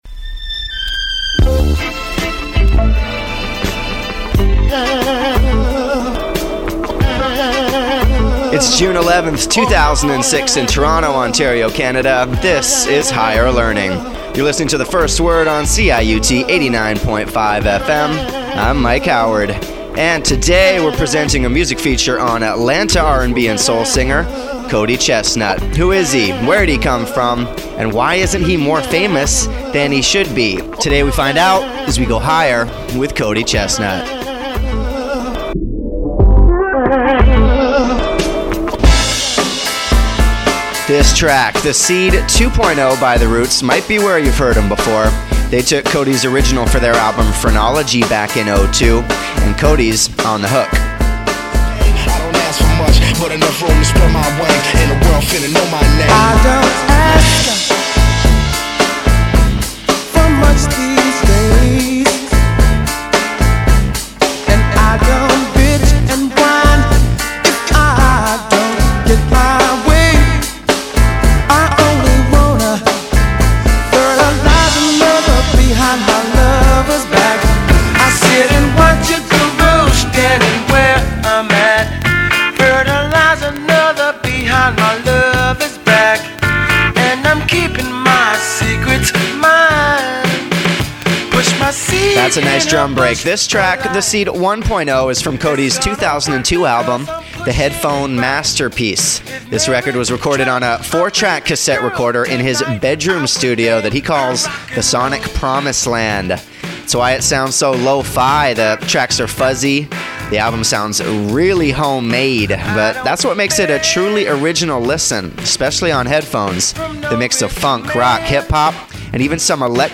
We give you some tracks, some background, and that Higher Learning magic touch;) This is the story of CC.